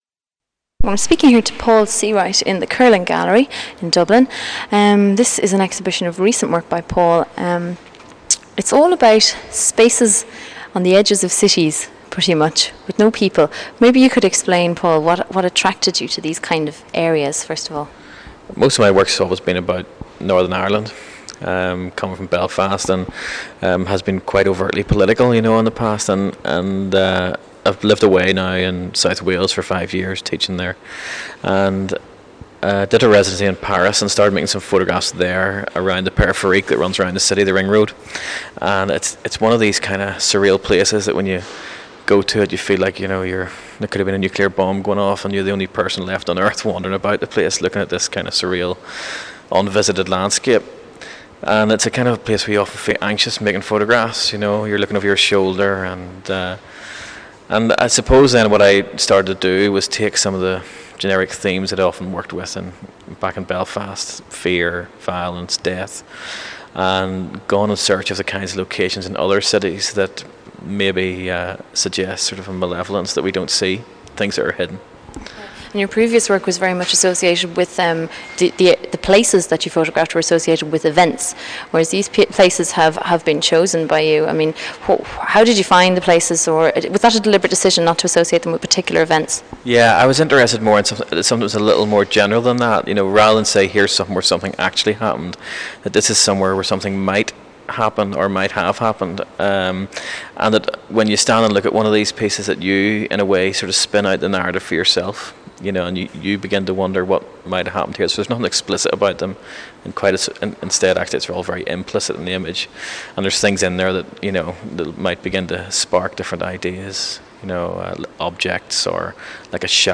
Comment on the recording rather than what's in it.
in the Kerlin Gallery, Dublin in 1999